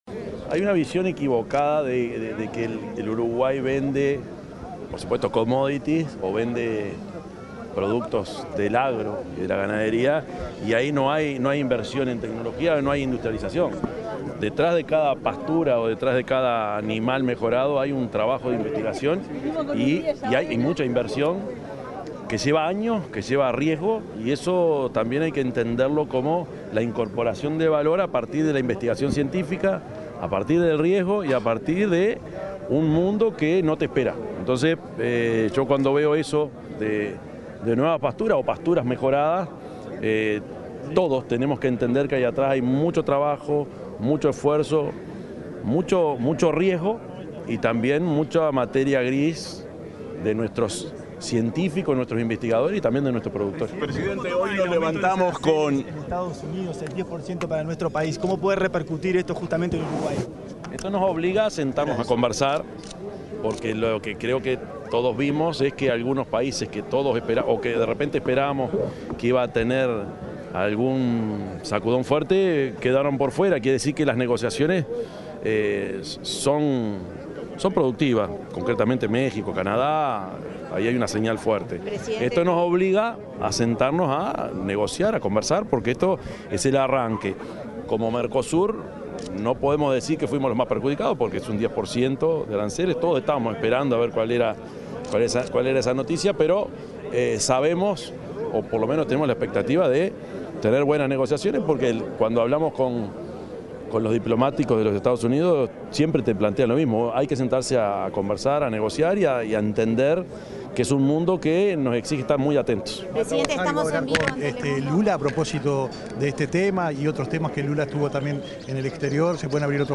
Declaraciones del presidente, Yamandú Orsi
Luego, dialogó con la prensa.